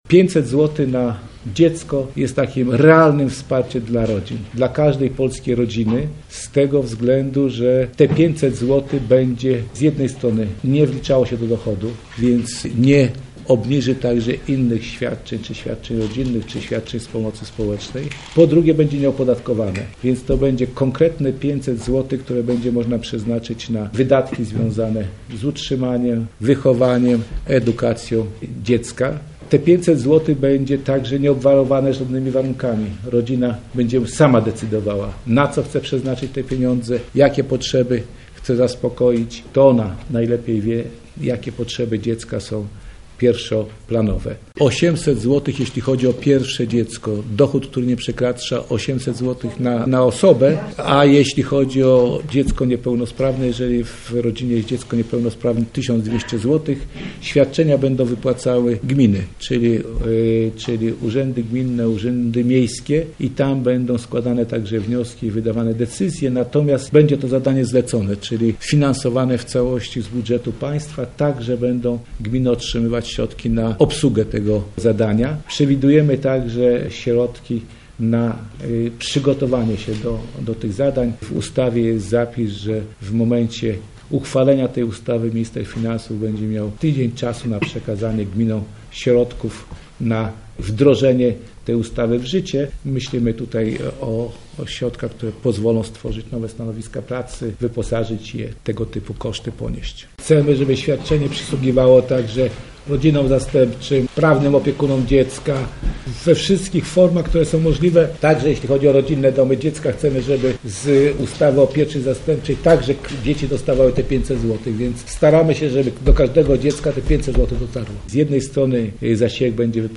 Dziś w Urzędzie Wojewódzkim w Lublinie odbyły się konsultacje społeczne w sprawie ustawy.
– mówi Krzysztof Michałkiewicz, sekretarz stanu w Ministerstwie Rodziny, Pracy i Polityki Społecznej.